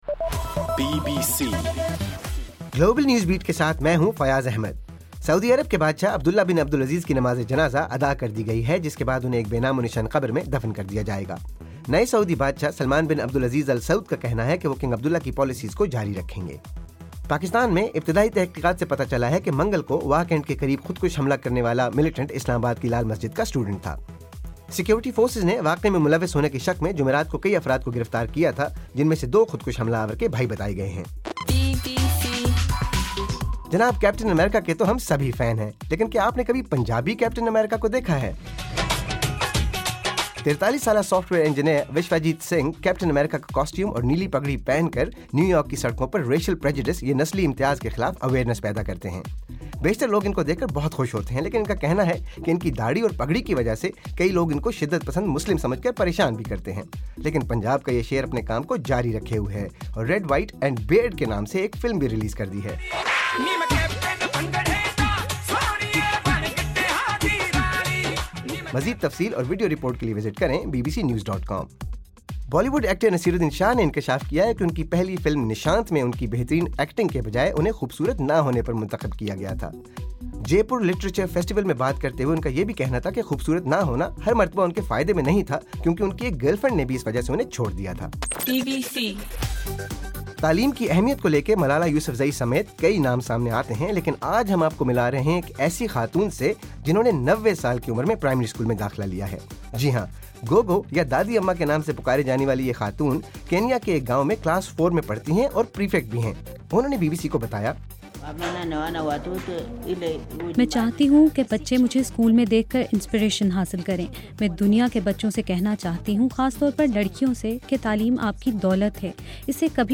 جنوری 23: رات 9 بجے کا گلوبل نیوز بیٹ بُلیٹن